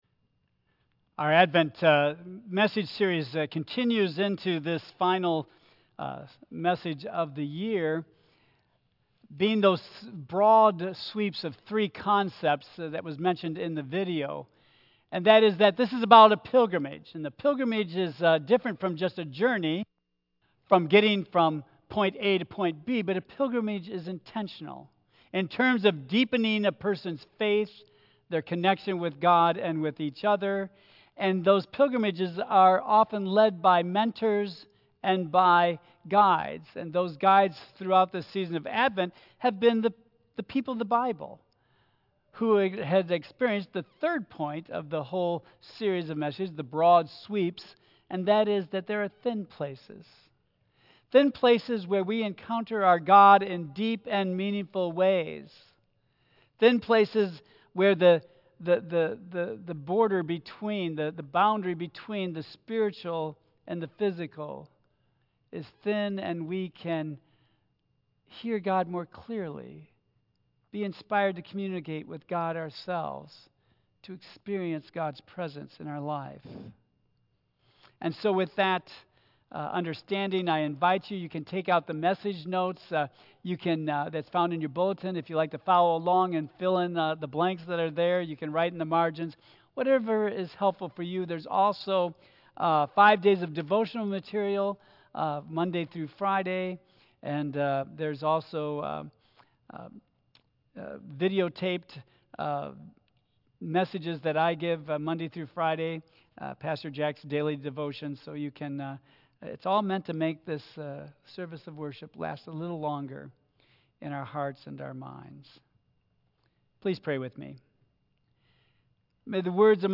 Tagged with Michigan , Sermon , Waterford Central United Methodist Church , Worship